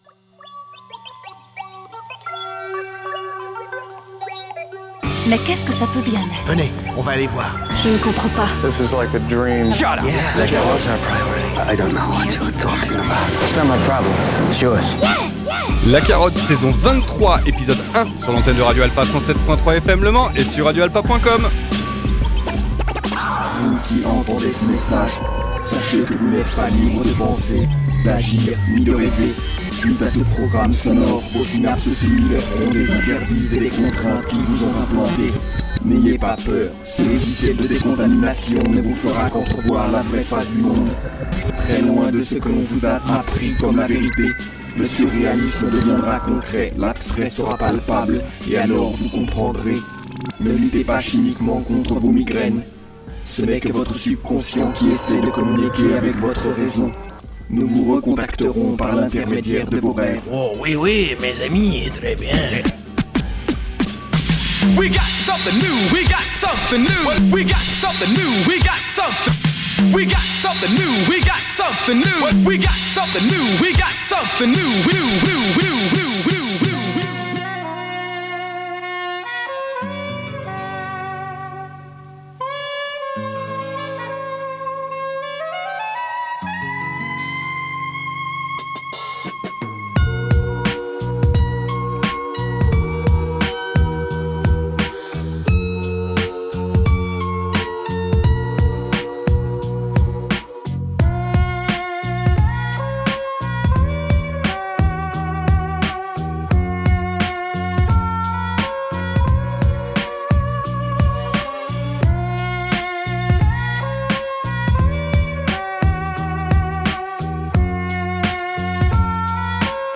Toujours des découvertes à grignoter auditivement dans un spectre qui tourne autour du boom bap et du groove pour cette émission de rentrée.
HIP-HOP